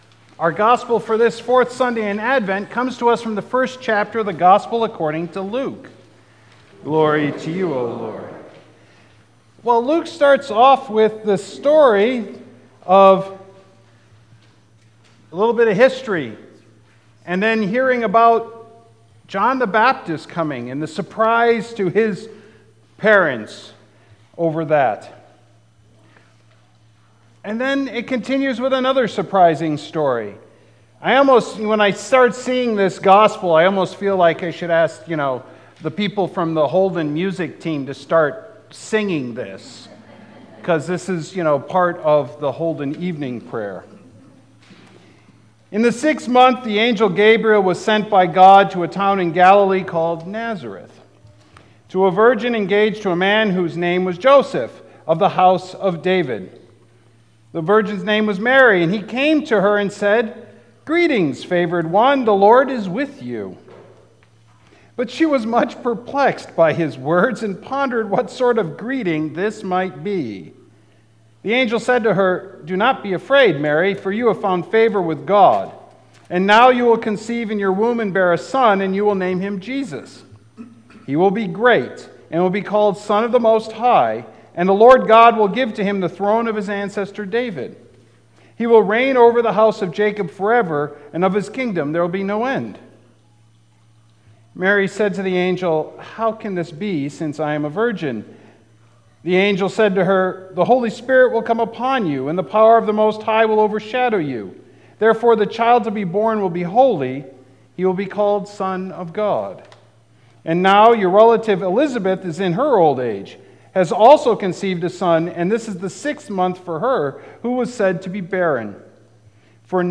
Sermons | Beautiful Savior Lutheran Church